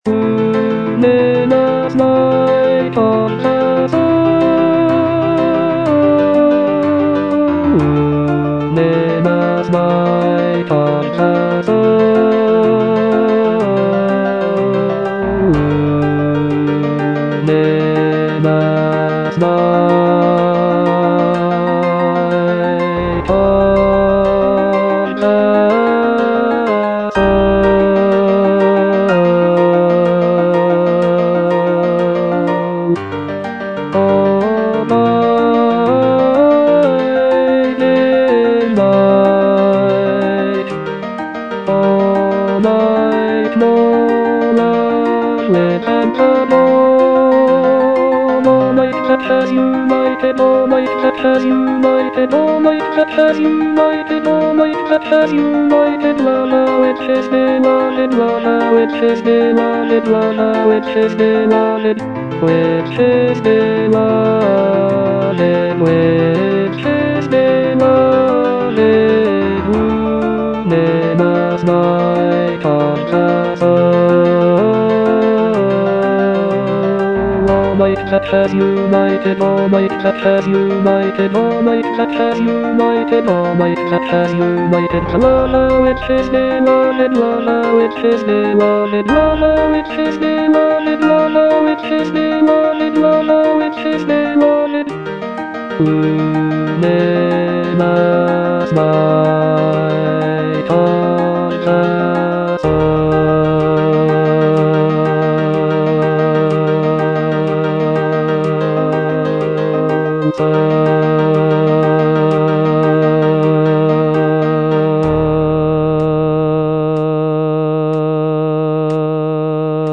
bass I) (Voice with metronome) Ads stop